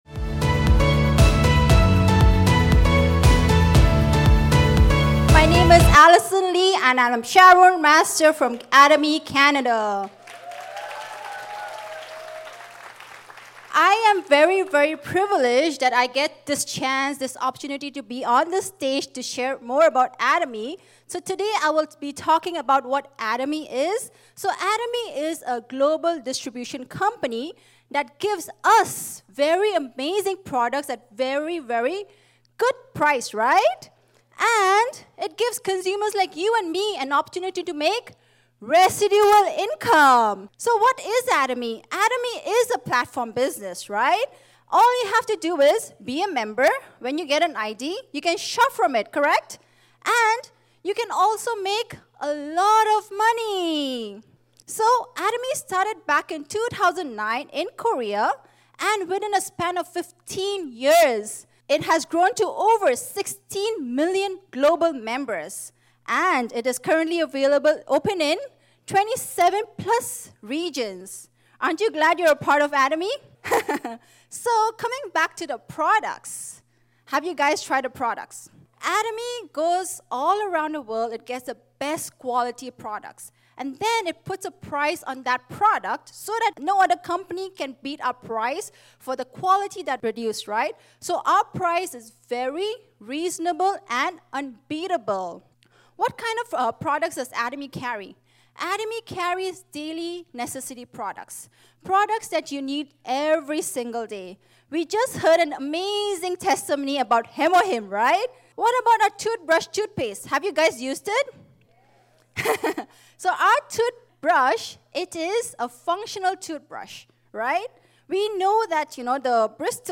Toronto Seminar